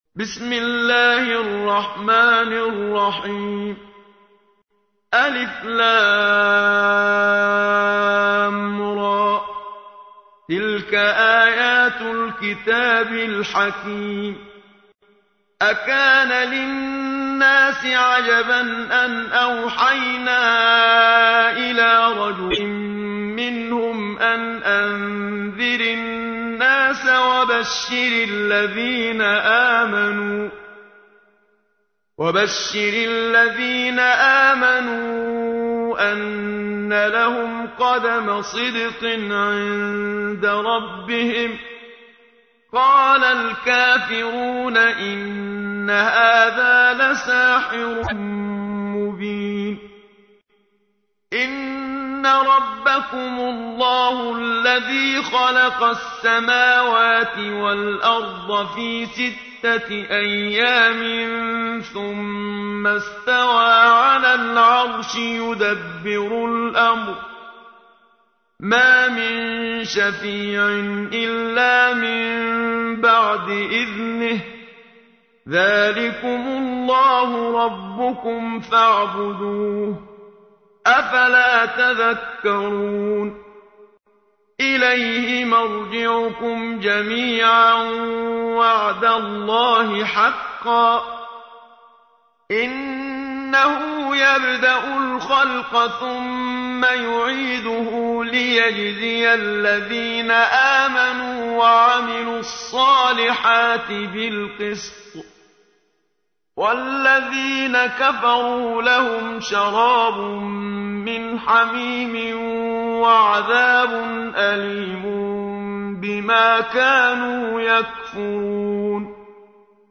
تحميل : 10. سورة يونس / القارئ محمد صديق المنشاوي / القرآن الكريم / موقع يا حسين